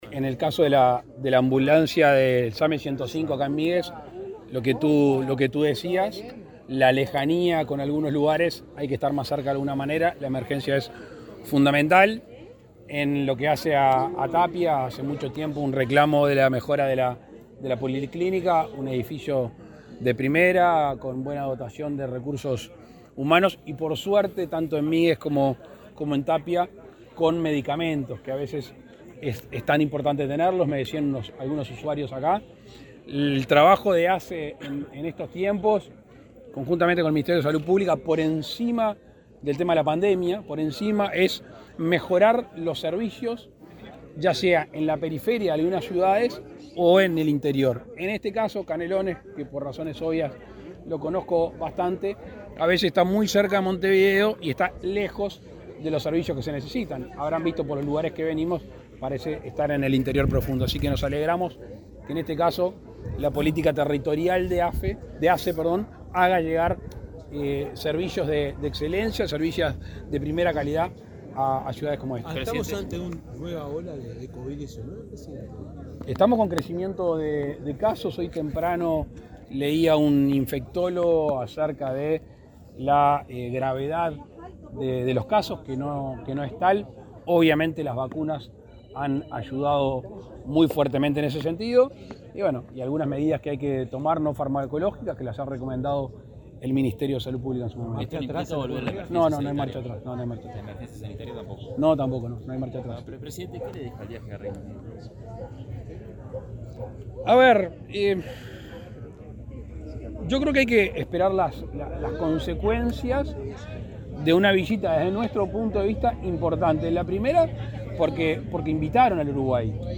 Declaraciones del presidente Lacalle Pou a la prensa
Luego, dialogó con la prensa.